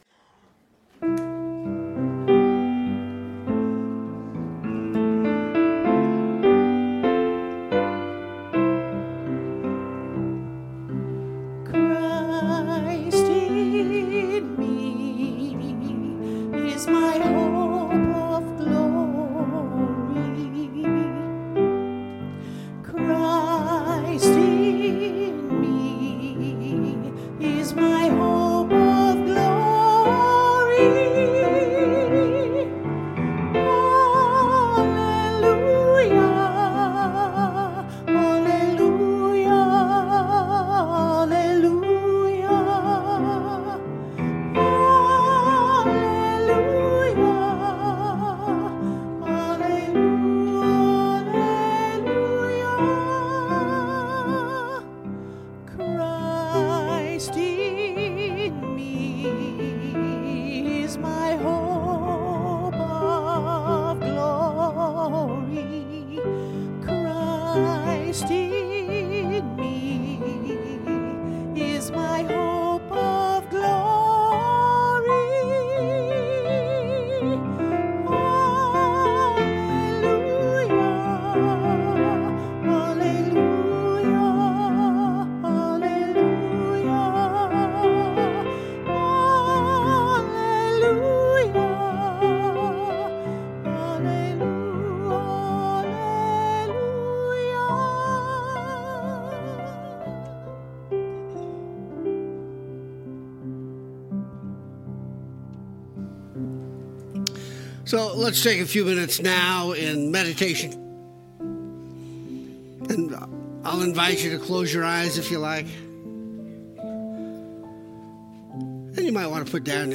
The audio recording (below the video clip) is an abbreviation of the service. It includes the Meditation, Lesson and Featured Song.